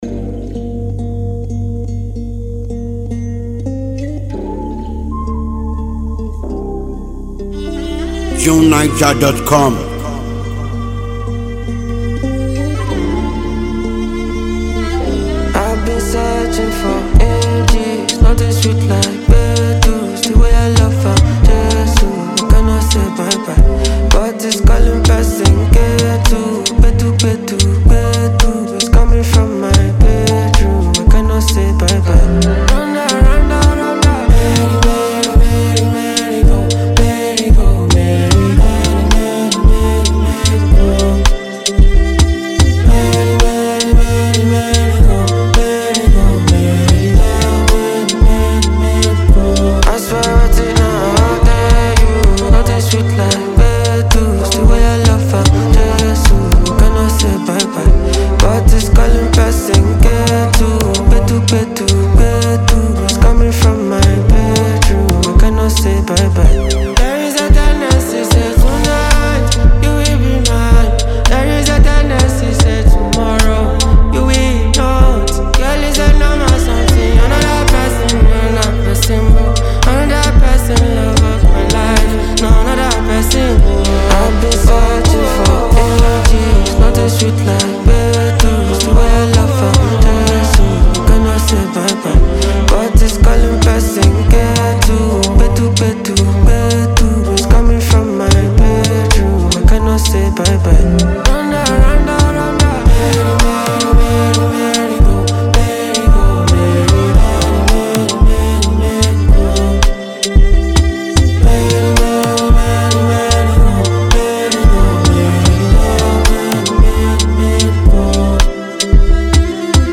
catchy and emotionally complex song